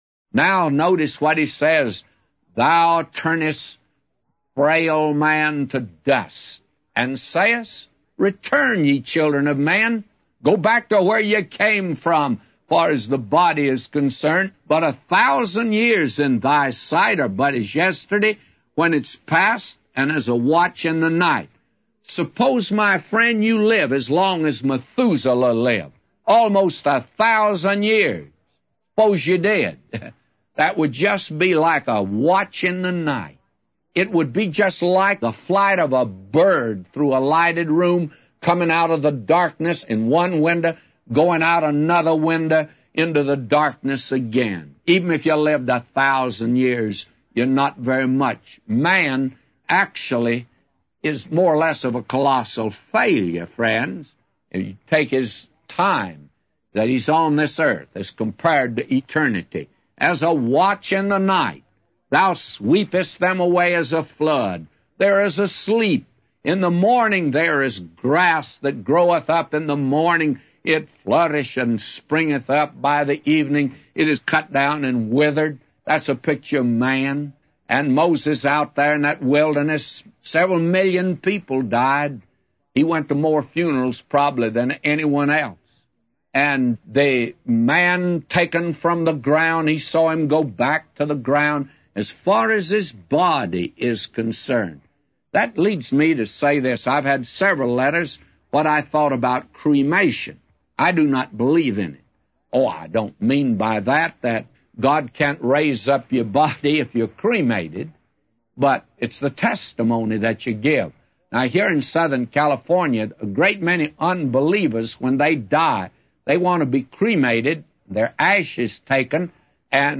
A Commentary By J Vernon MCgee For Psalms 90:3-999